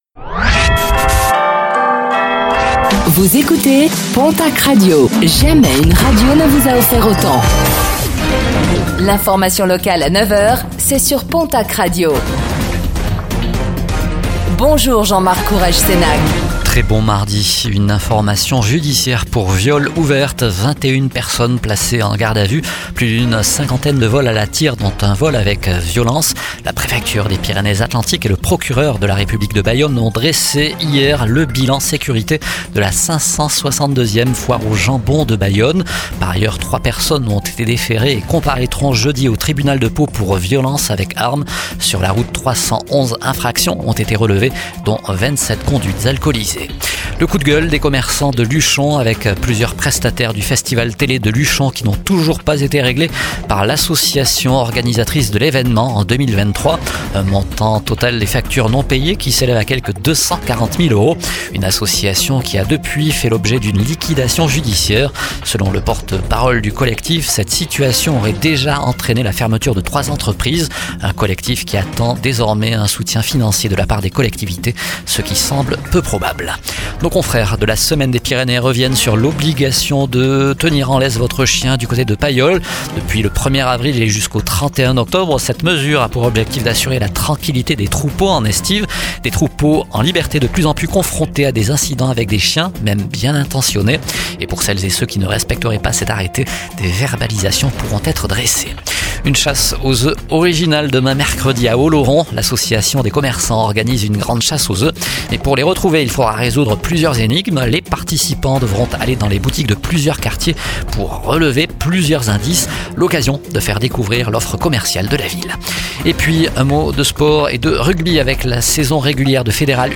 Réécoutez le flash d'information locale de ce mardi 15 avril 2025